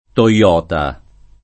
Toyota [giapp. t1Lota] top. (Giapp.) — città, che dà nome all’industria automobilistica detta in ingl. Toyota motor corporation [toi-ë^utë më^utë koopër$iš-n] e in it., di solito, la Toyota [